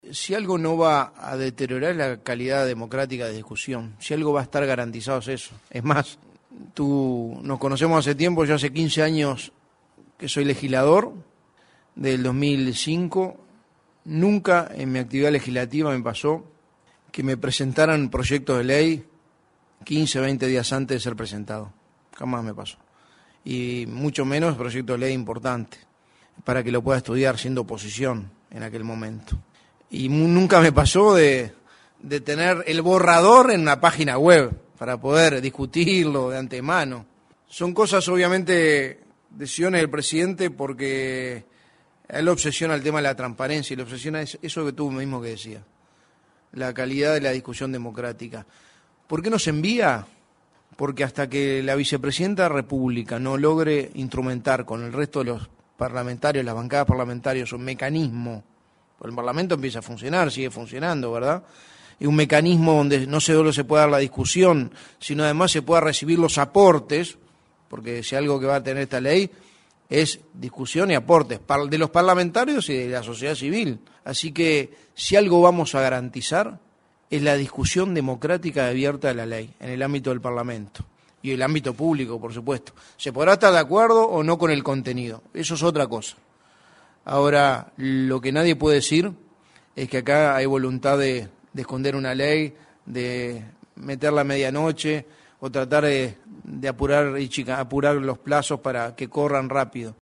Por su parte, el secretario de Presidencia, Álvaro Delgado, fue consultado en conferencia acerca del envío de la Ley de Urgente Consideración. Dijo que en los 15 años que fue legislador, nunca le presentaron un proyecto de ley 15 o 20 días antes.